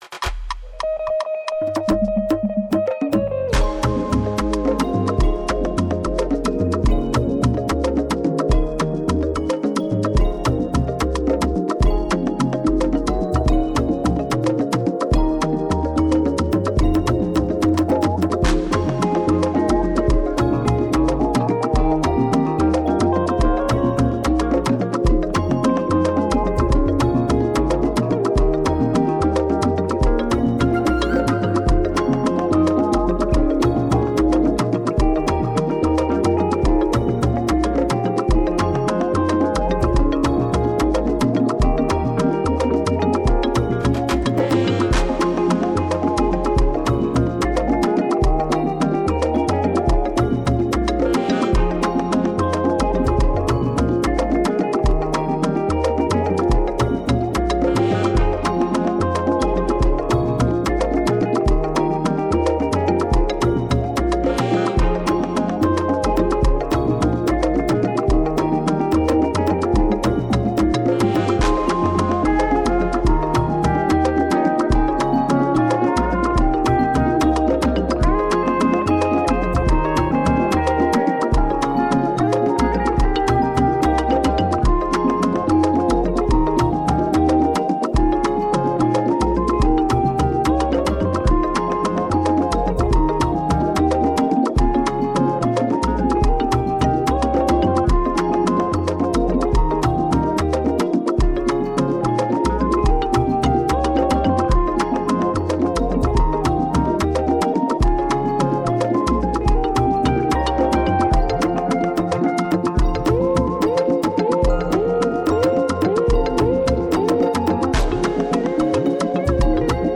Igbo Gospel Music
a powerful worship song to uplift and inspire you.